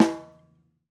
Érdemes megjegyezni, hogy a pergő hangzás még így sem az igazi, de amikor igazán tökös pergőhangot akartam alákeverni az eredetinek, valahogy mindig kilógott a lóláb, és nem éreztem oda illőnek - túl művi, túl gépiesnek hatott mindig.